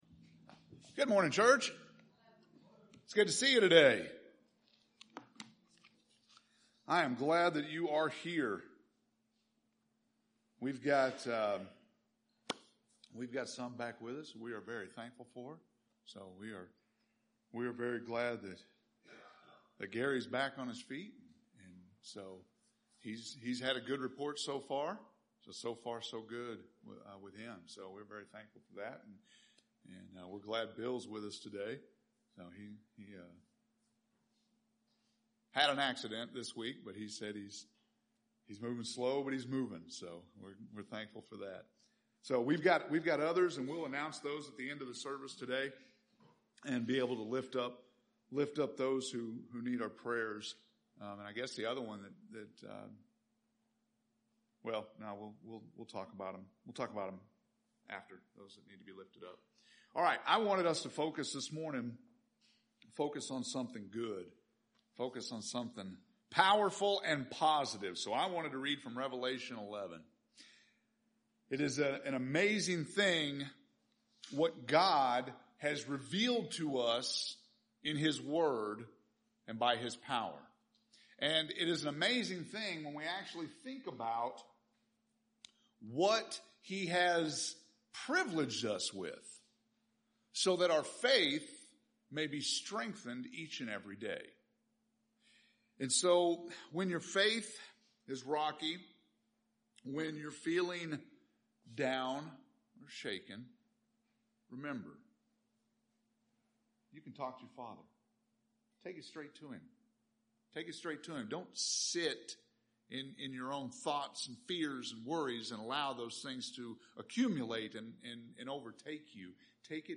August 16th – Sermons